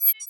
UI SCI-FI Tone Bright Dry 01 (stereo).wav